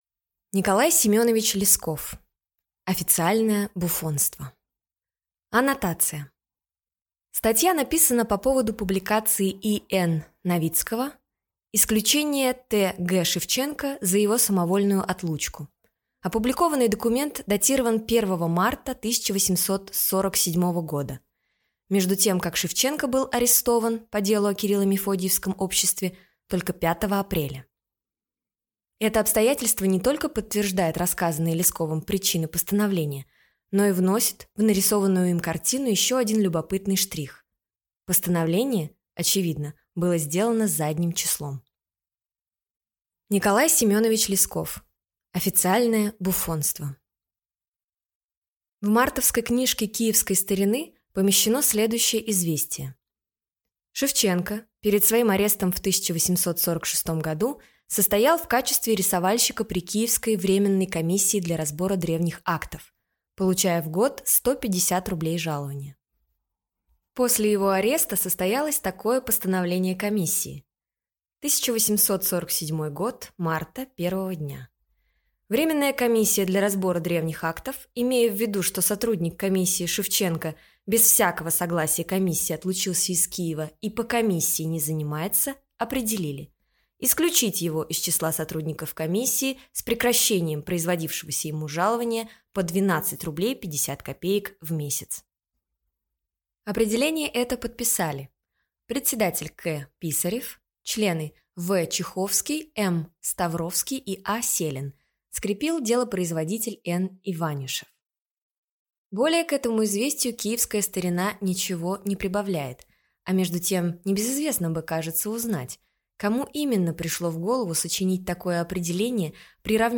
Аудиокнига Официальное буффонство | Библиотека аудиокниг